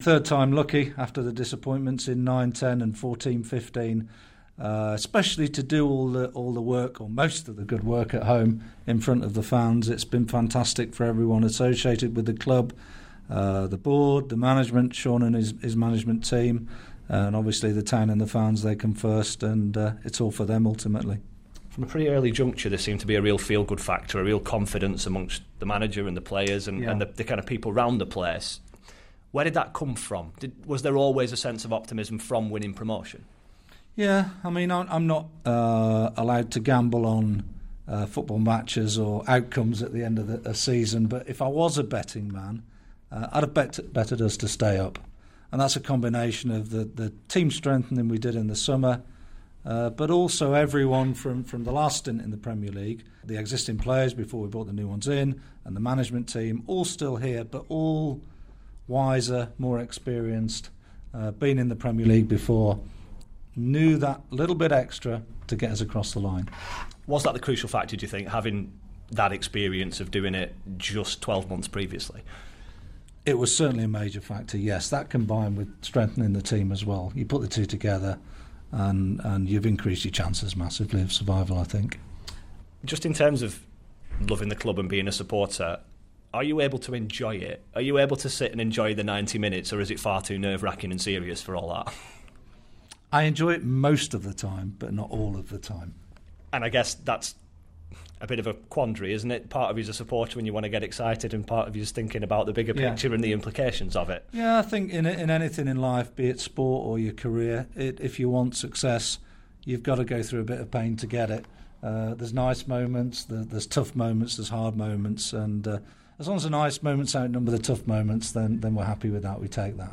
gives a specially extended interview